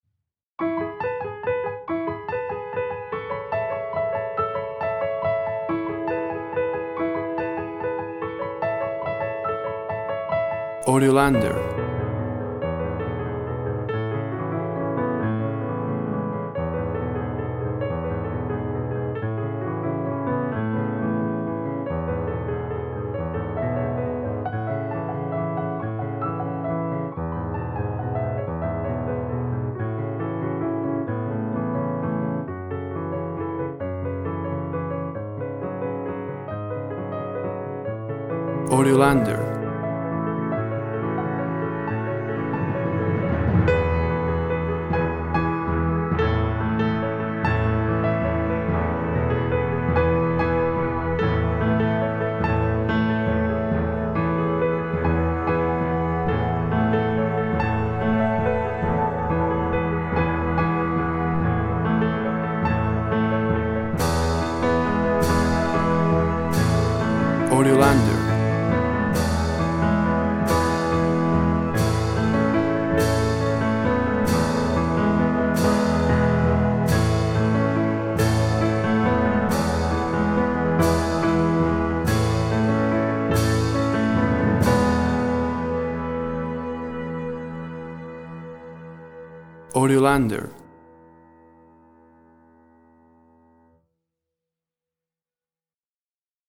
Tempo (BPM) 100